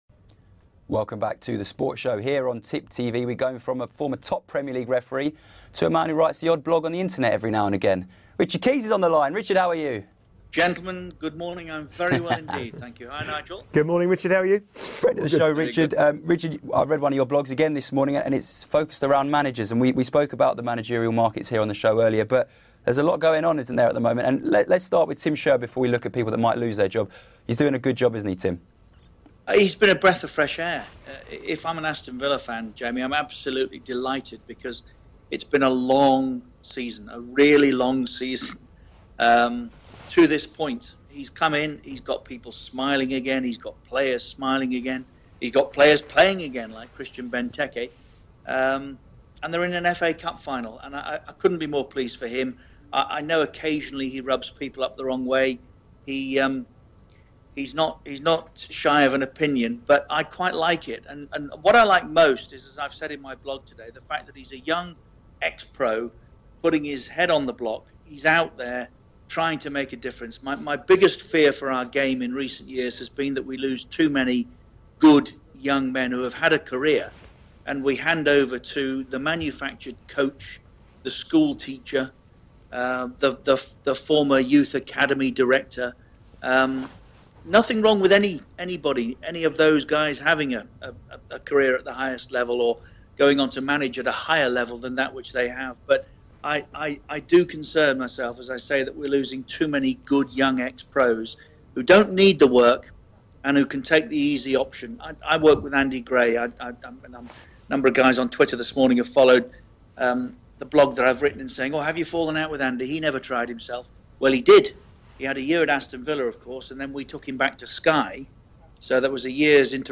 Broadcasting Legend and friend of the show Richard Keys gives us his thoughts on the potential summer, managerial merry-go-round. Will Pellegrini lose his job?